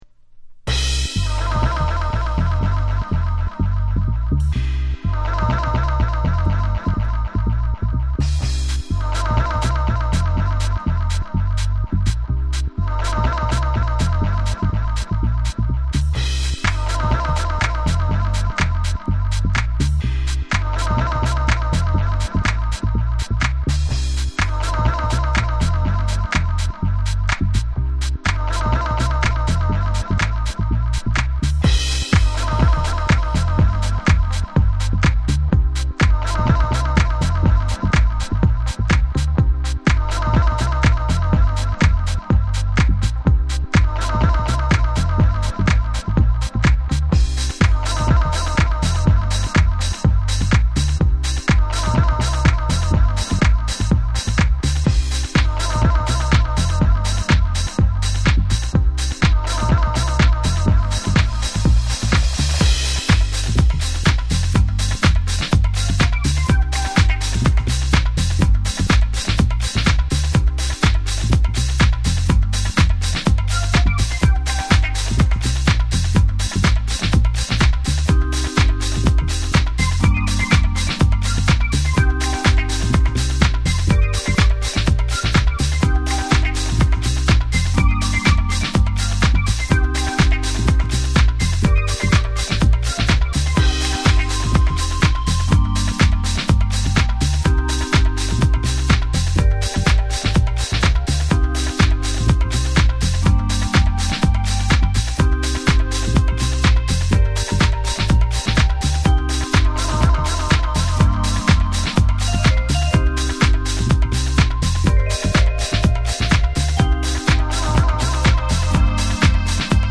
頭から尻尾までドントストップなグルーヴキープ力のあるサンプリングハウス。サンプリング粒子飛び散るグレイトディスコハウス。